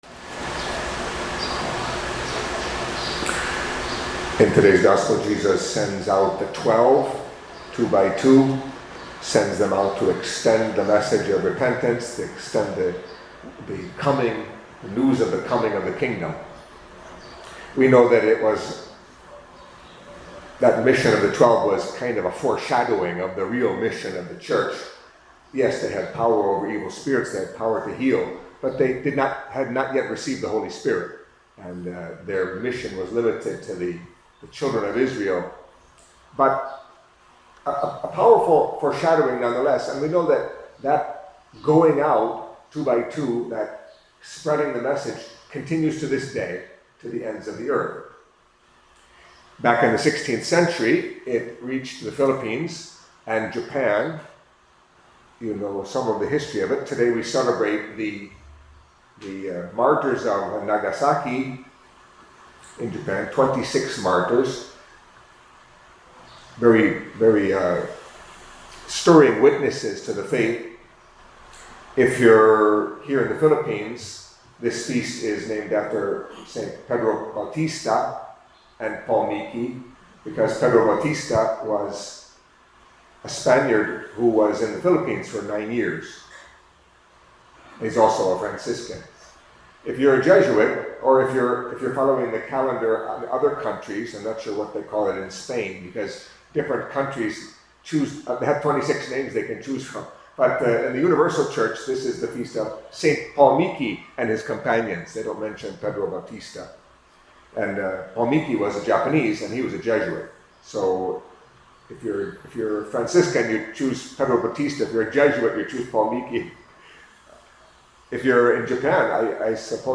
Catholic Mass homily for Thursday of the Fourth Week in Ordinary Time